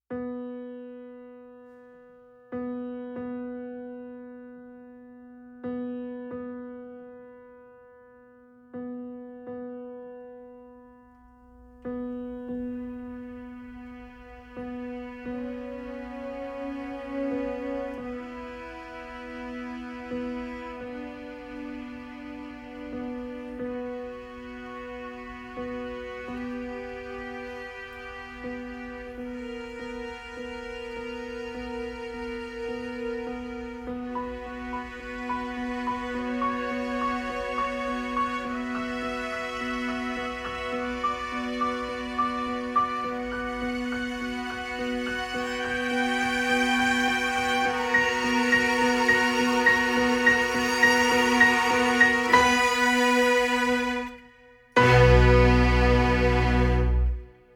SymphonicRock / ElectronicRock / AlternativeRock